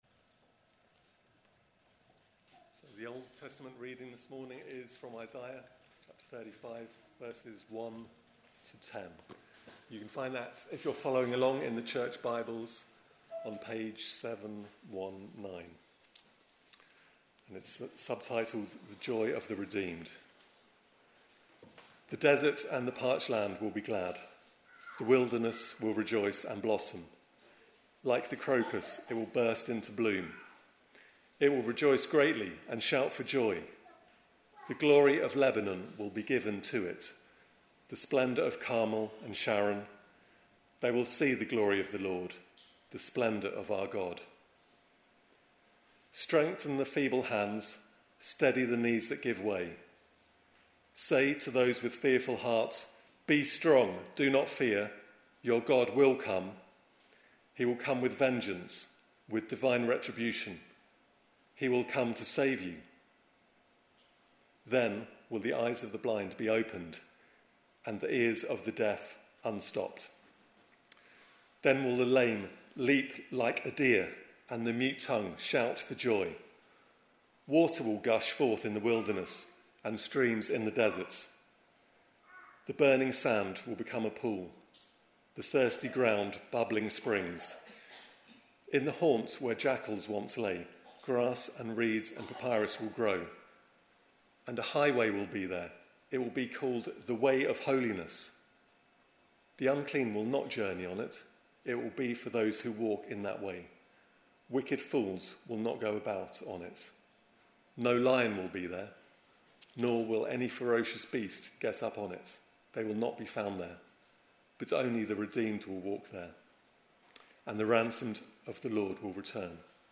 Readings and Sermon Sunday 19 November 2023 – Holy Trinity Church Cannes